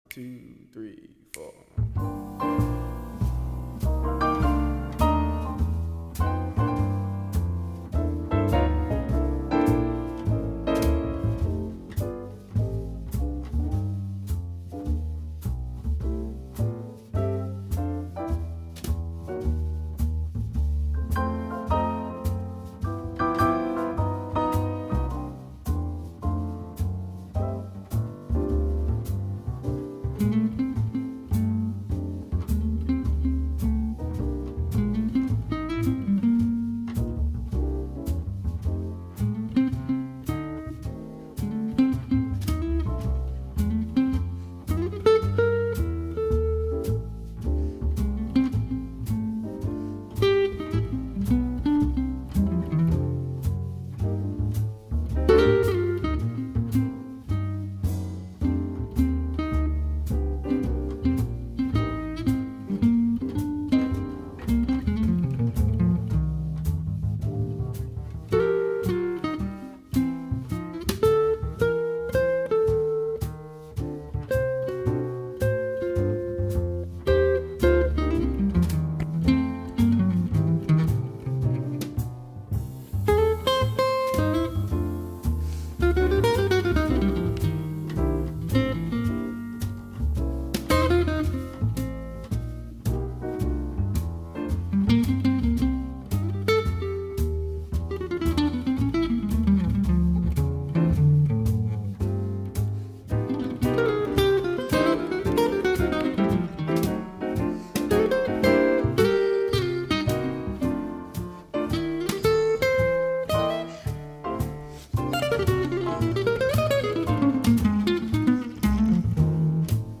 cool jazz set Program Type